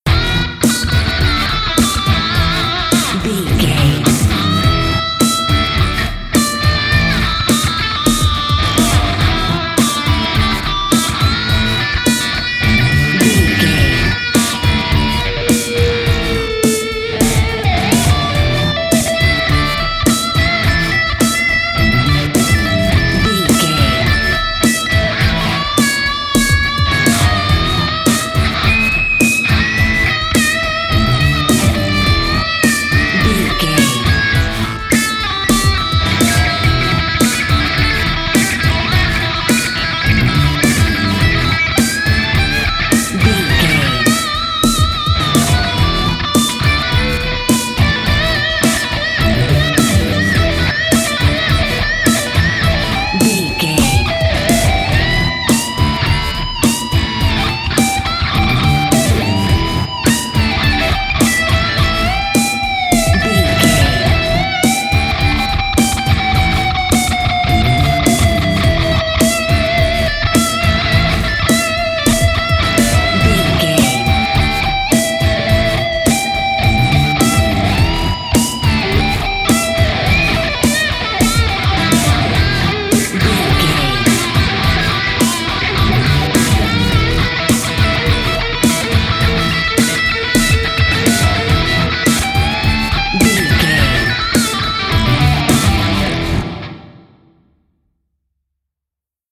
Epic / Action
Aeolian/Minor
hard rock
lead guitar
bass
drums
aggressive
energetic
intense
nu metal
alternative metal